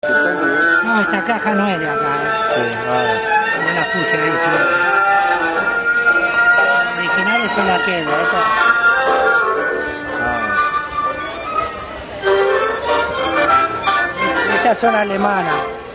Hier ist das Grammophon zwar nicht zu sehen, aber in 5 Folgen zu hören.